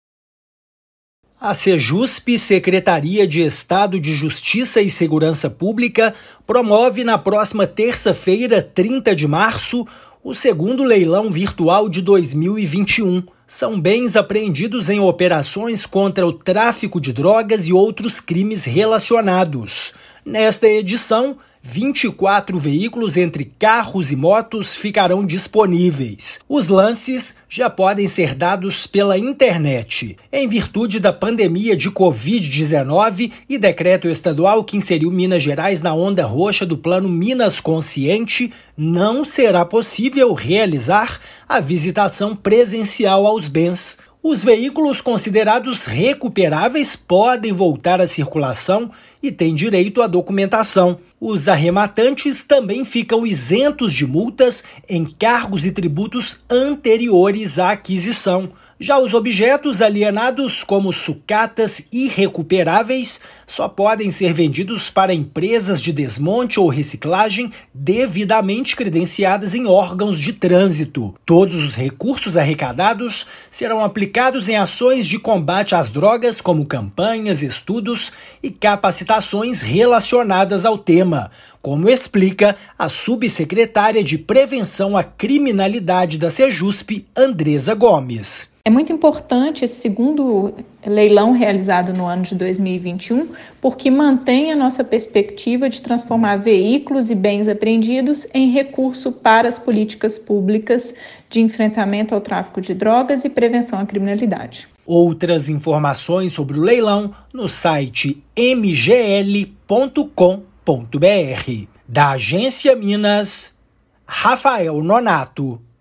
Ação busca transformar itens recuperados em recursos para a execução de campanhas, capacitações e estudos relacionados à temática das drogas. Ouça a matéria de rádio.
MATÉRIA_RÁDIO_LEILÃO_SEJUSP.mp3